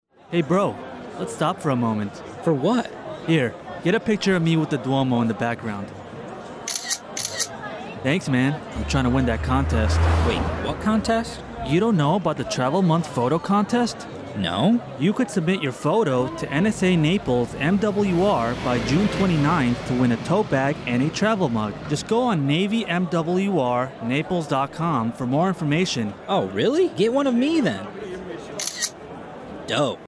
Radio spot highlighting the Travel Month photo contest hosted by MWR Naples for the month of June.